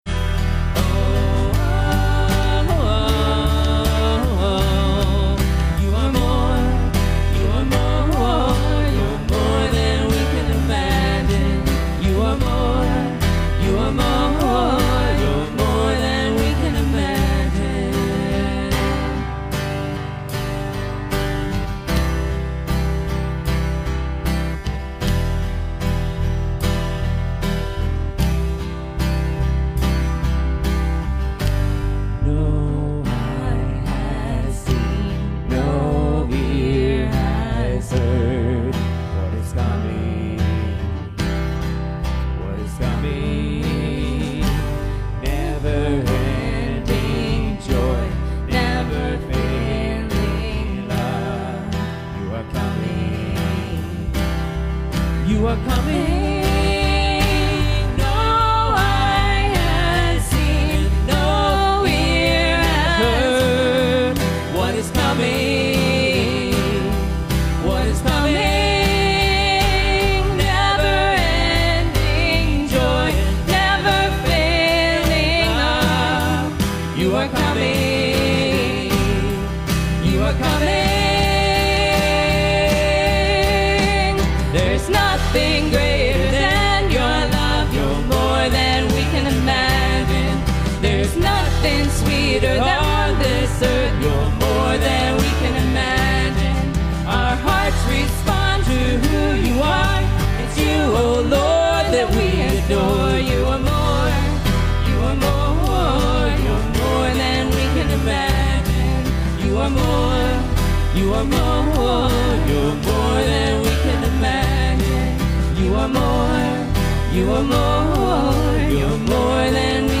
Passage: Ephesians 4:28 Service Type: Sunday Morning